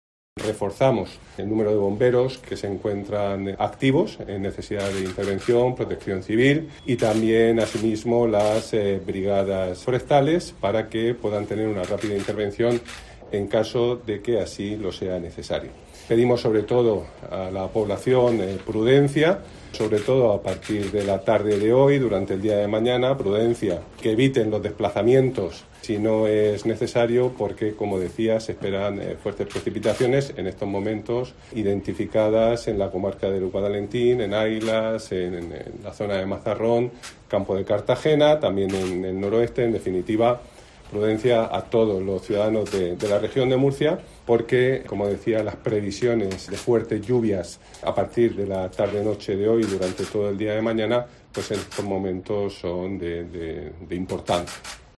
Juan María Vázquez, consejero de Emergencias